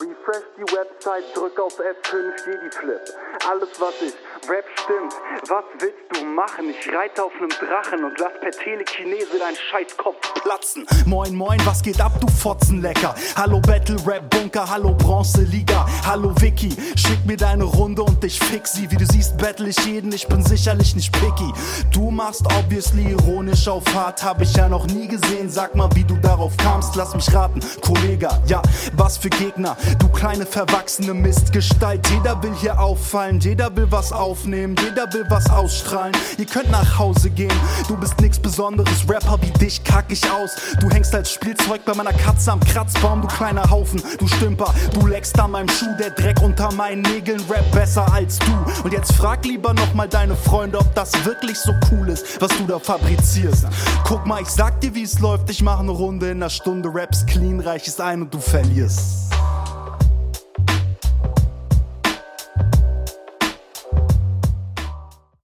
Der Flow kommt actually cool.